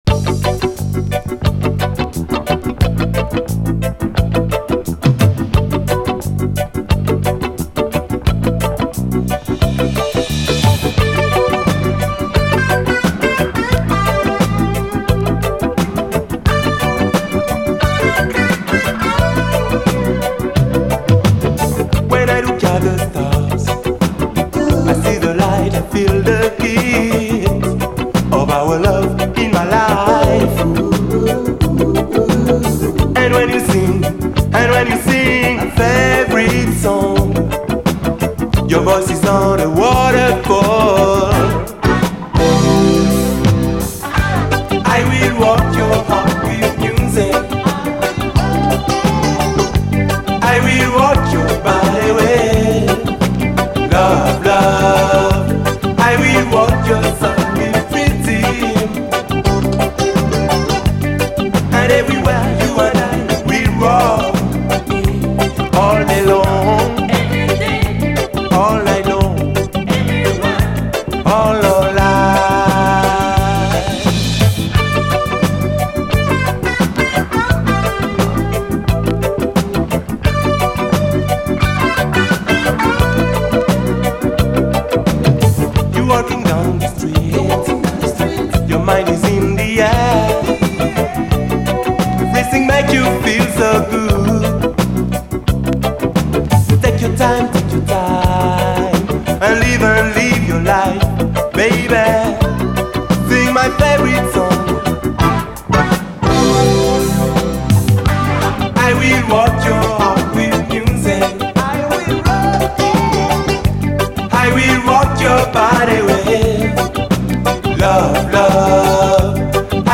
AFRO, REGGAE, WORLD
フレンチ・アフロ・レゲエ！
爽やかな南国AORフレーヴァーのトロピカル・レゲエ
フワフワとライトで洒落たサウンド！